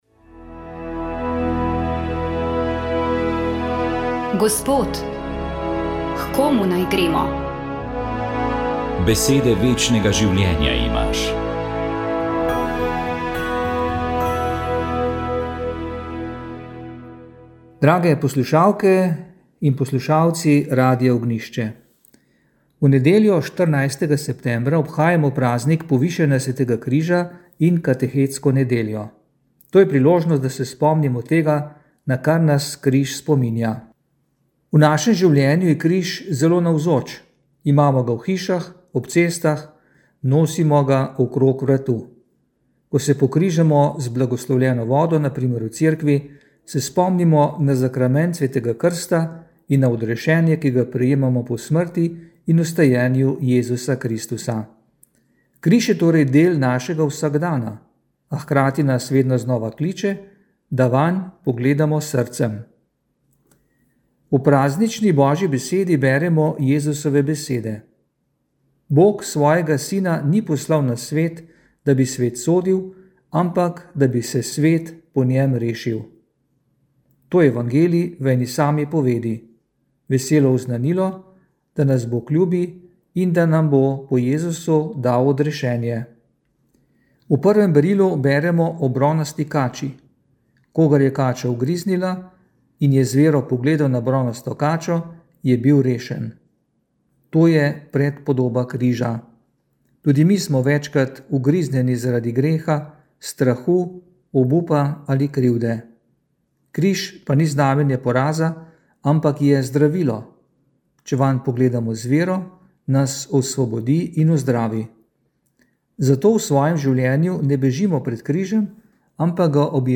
Duhovni nagovor
Duhovni nagovor je pripravil upokojeni ljubljanski nadškof dr. Anton Stres.